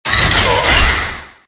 Cri de Melmetal dans Pokémon : Let's Go, Pikachu et Let's Go, Évoli.
Cri_0809_LGPE.ogg